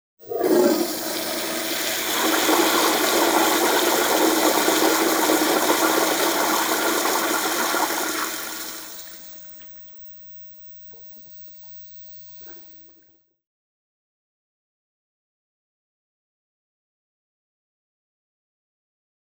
Traditionelle Spülung Standardspülung Moderne Spülung